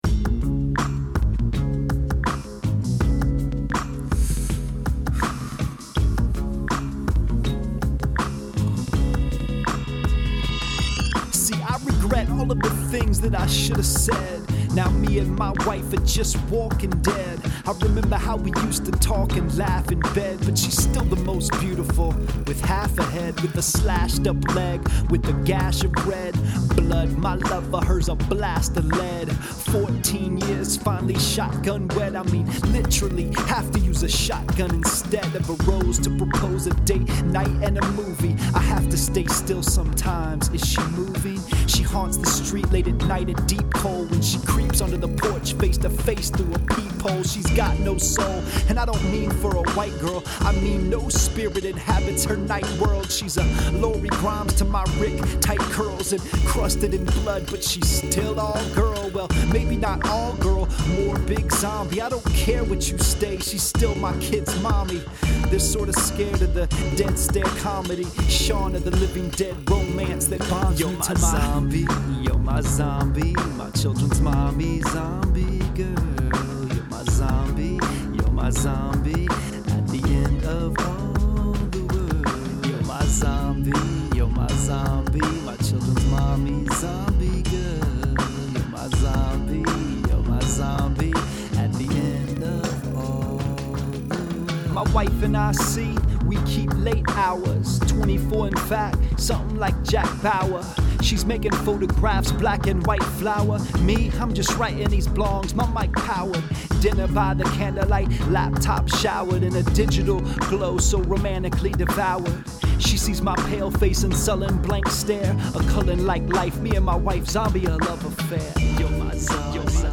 I don’t think I’ve ever written my wife a love song.